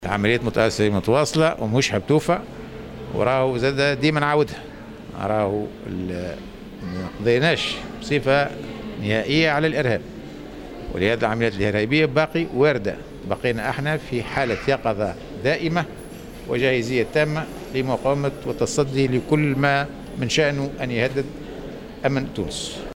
قال وزير الدفاع الوطني، عبد الكريم الزبيدي في تصريح لمراسل "الجوهرة أف أم" على هامش زيارة أداها اليوم إلى ولاية الكاف إن العمليات العسكرية متواصلة لملاحقة العناصر الإرهابية.